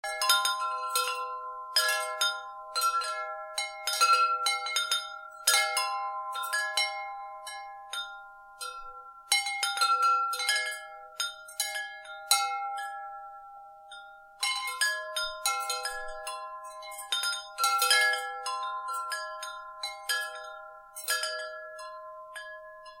Windspiel "Athen", 95 cm
Größe M, Bambusring mit 5 Metallröhren
Dieses wunderschöne Klangspiel vereint Bambus und Aluminium zu einer harmonischen Poesie aus liebreizender Optik und meditativer Akustik.
Die zentral hängende Kugel und die konzentrisch angeordneten Aluminiumstäbe verleihen diesem Windspiel seinen besonderen Charakter und tragen maßgeblich zum Zauber dieses wundervollen Klang- & Windspieles bei.
Klangbeispiel